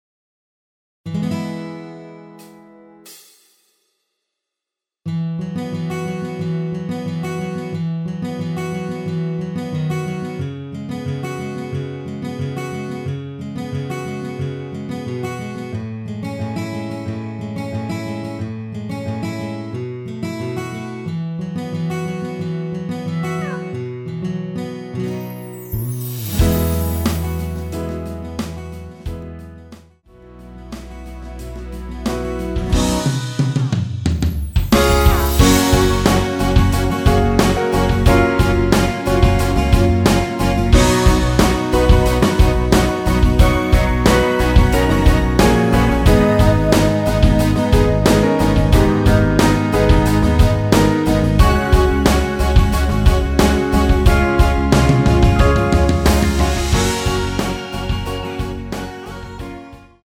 전주 없이 시작 하는곡이라 인트로 4박 만들어 놓았습니다.(미리듣기 참조)
앞부분30초, 뒷부분30초씩 편집해서 올려 드리고 있습니다.
중간에 음이 끈어지고 다시 나오는 이유는